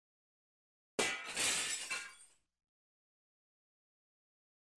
VIDRIO ROTOVIDRIO ROTO
Tonos gratis para tu telefono – NUEVOS EFECTOS DE SONIDO DE AMBIENTE de VIDRIO ROTOVIDRIO ROTO
Ambient sound effects
Vidrio_rotovidrio_roto.mp3